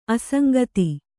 ♪ asaŋgati